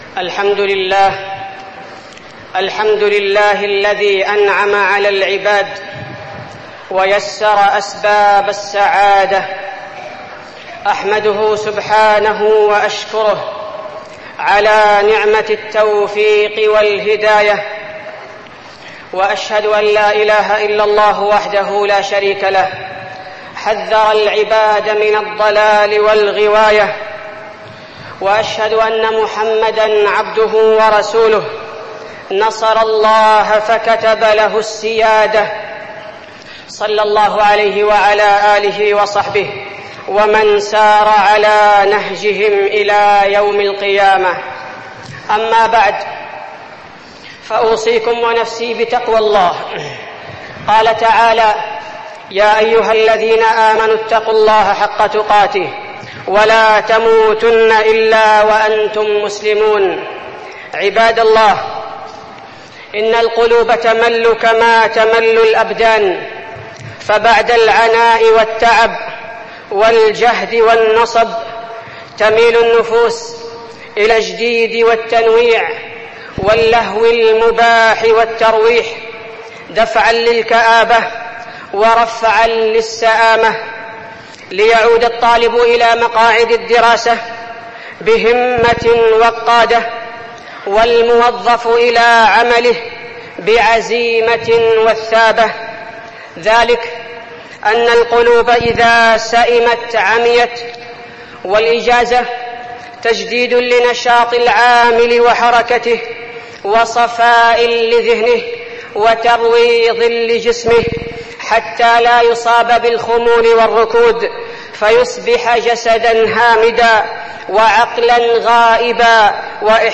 خطبة الترويح عن النفس وقضاء الإجازة وفيها: أهمية الترويح عن النفس، وحال النبي وحثه في الترويح عن الصحابة، وخطورة الإفراط في اللهو المباح
تاريخ النشر ٩ صفر ١٤٠٩ المكان: المسجد النبوي الشيخ: فضيلة الشيخ عبدالباري الثبيتي فضيلة الشيخ عبدالباري الثبيتي الترويح عن النفس وقضاء الإجازة